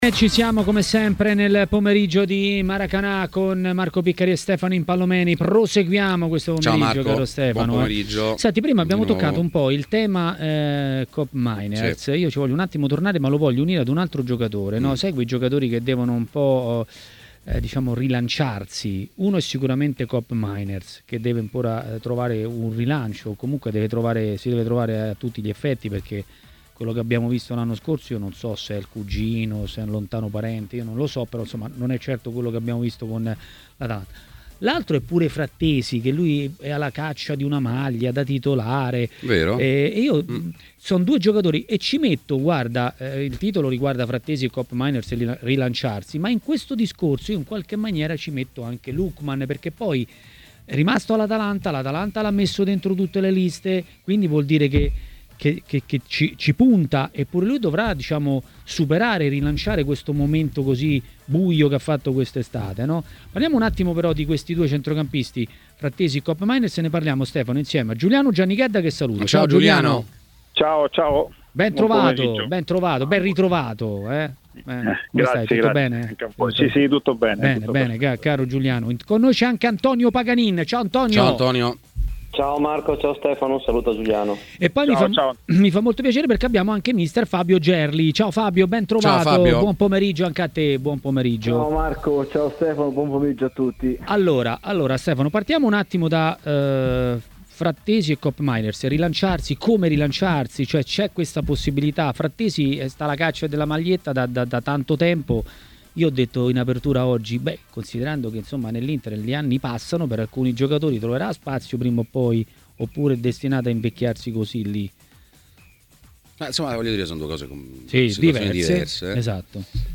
L'ex calciatore e tecnico Giuliano Giannichedda ha parlato dei temi di giornata a TMW Radio, durante Maracanà.